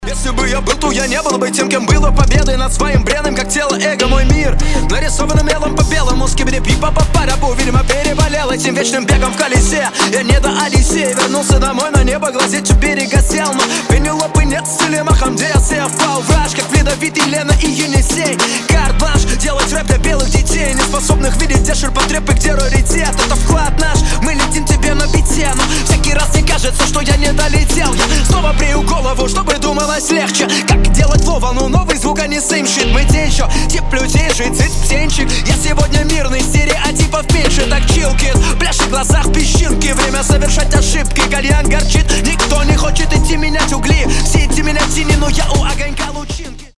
Хип-хоп
русский рэп
речитатив